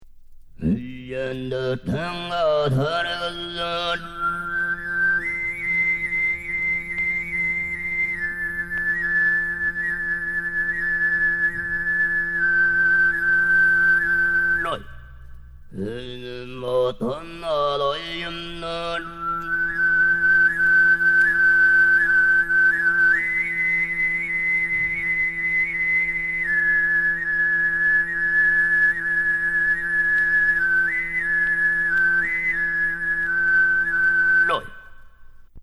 Аутентичные звукозаписи из собрания Фольклорной комиссии и частных коллекций предназначены для включения в энциклопедию на CD-ROM.
Произведенные иногда десятилетия назад на несовершенной технике, они доносят до нас живые голоса самобытных культур малочисленных коренных народов, которые ассимилируются сегодня в унифицированной социальной среде агрессивной "массовой культурой".
ТУВИНЦЫ "Река Алаш". Горловое пение в стиле "сыгыт". Исп. Ооржак Хунаштаар-оол.